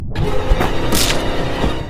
Among Us Death 2 Sound Effect Free Download